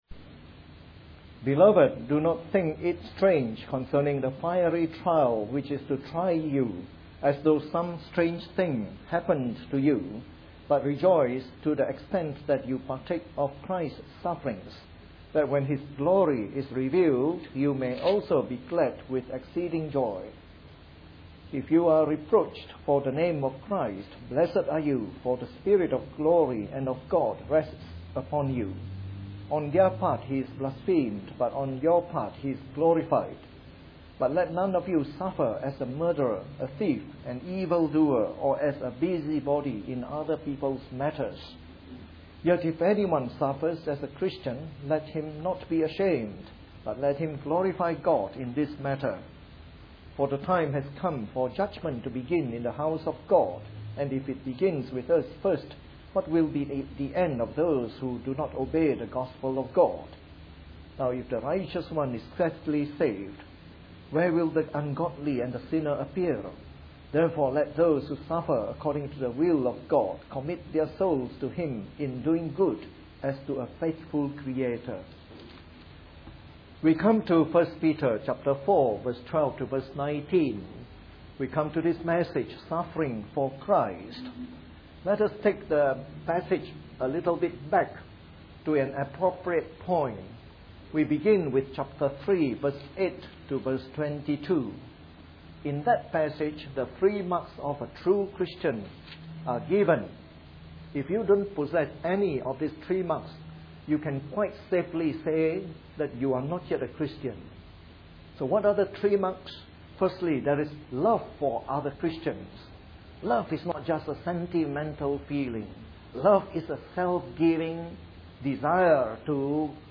Part of our series on “The Epistles of Peter” delivered in the Evening Service.